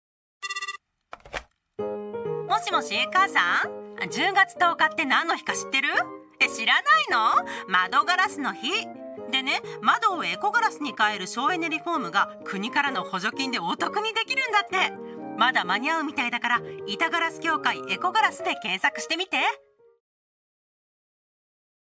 ・「10月10日は窓ガラスの日」にラジオCMを実施しました。 10月1日から10月10日までの期間、 「10月10日は窓ガラスの日」 と 「窓の省エネリフォーム補助金活用」 のプロモーションで ラジオＣＭを実施 しました。